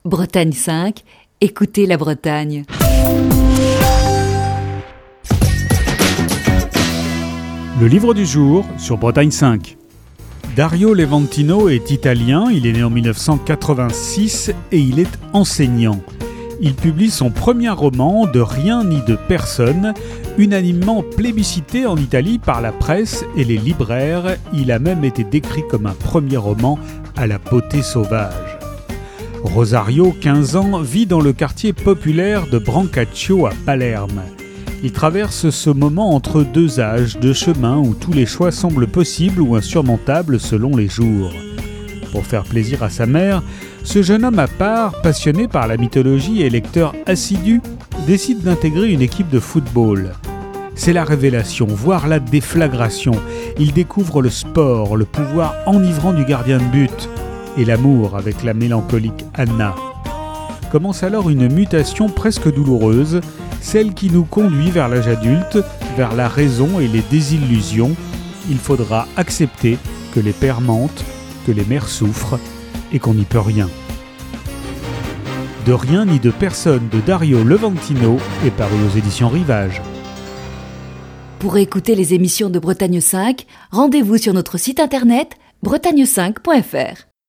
Chronique du 10 avril 2020.